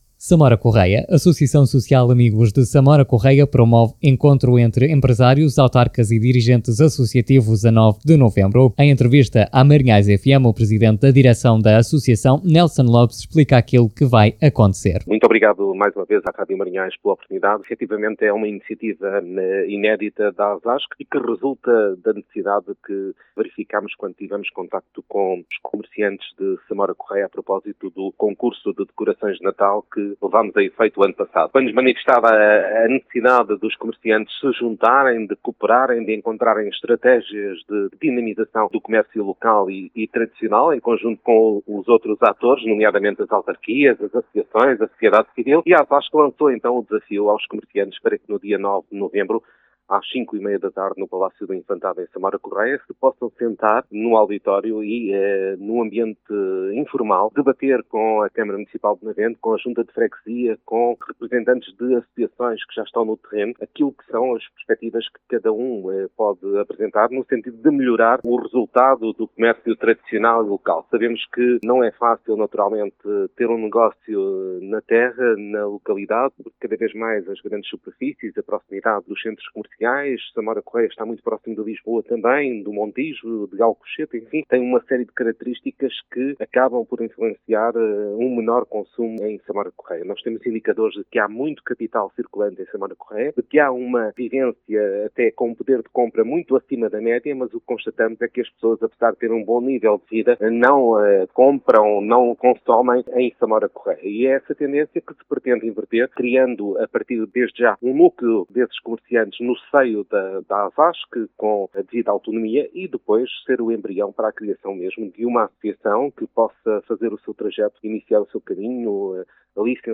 declarações